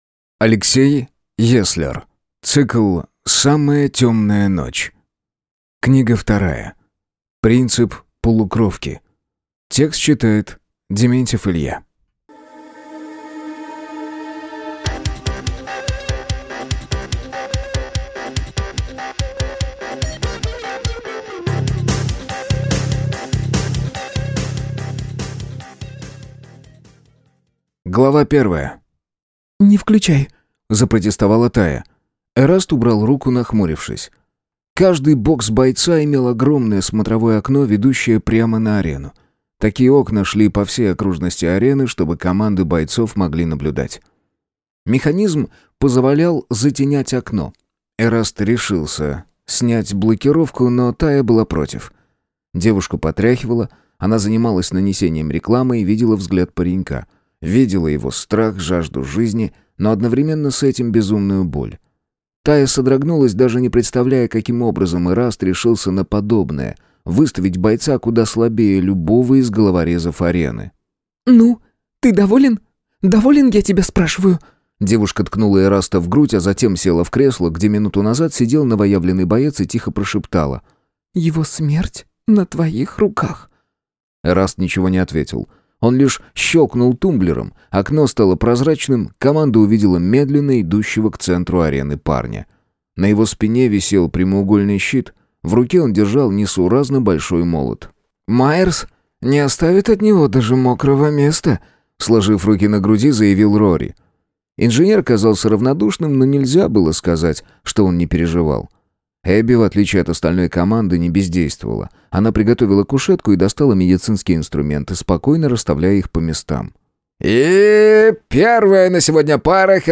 Аудиокнига Принцип полукровки | Библиотека аудиокниг